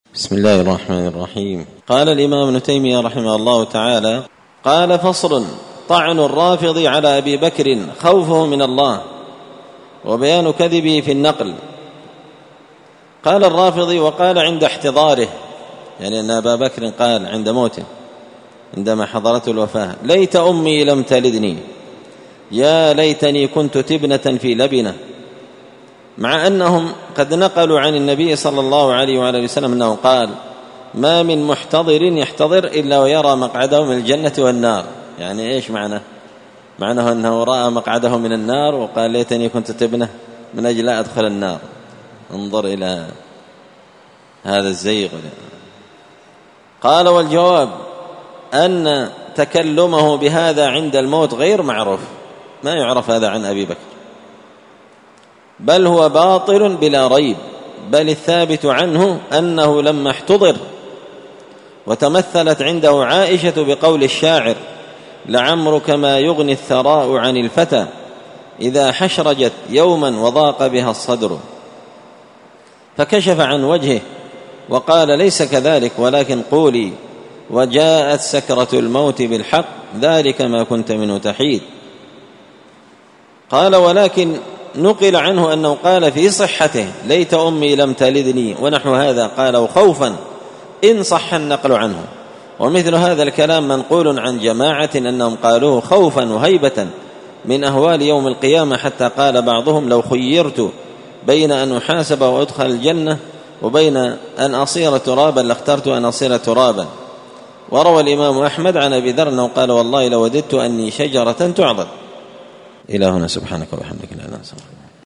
الدروس
مسجد الفرقان قشن_المهرة_اليمن